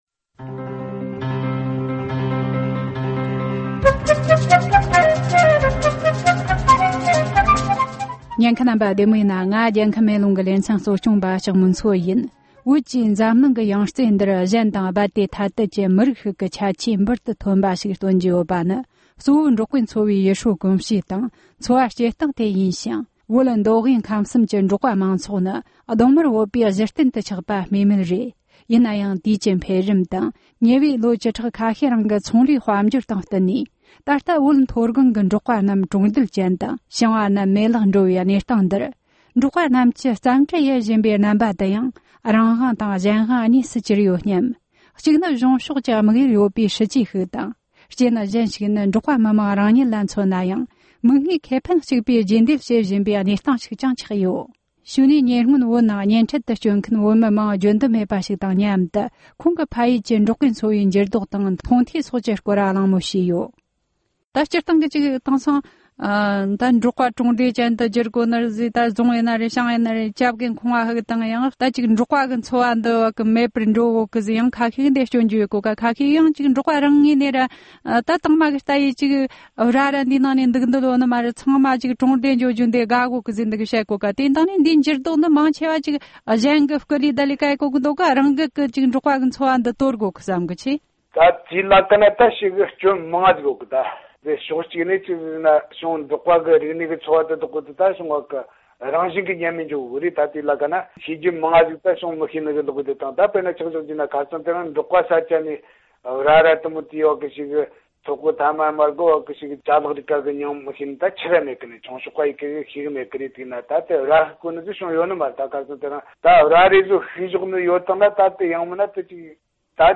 སྒྲ་ལྡན་གསར་འགྱུར།
གླེང་མོལ་